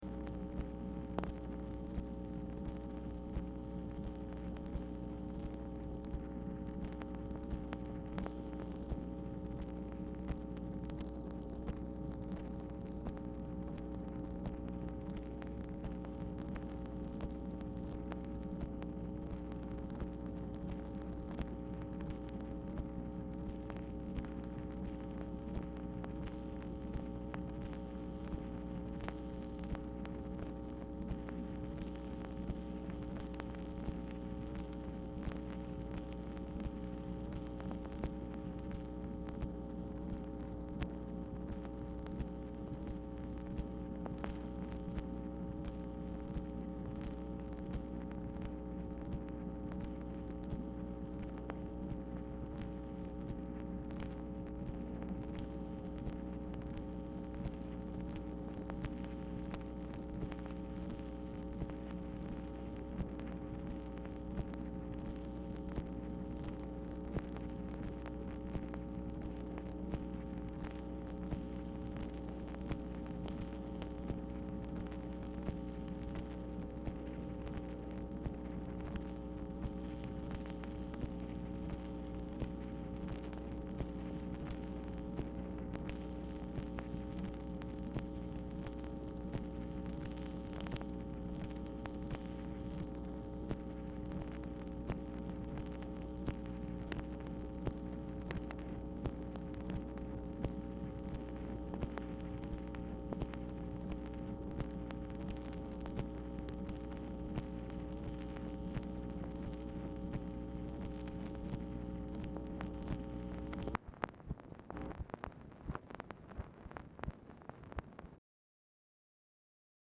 Telephone conversation # 11121, sound recording, MACHINE NOISE, 12/10/1966, time unknown | Discover LBJ
Title Telephone conversation # 11121, sound recording, MACHINE NOISE, 12/10/1966, time unknown Archivist General Note "LOS ANGELES, CALIF."
Dictation belt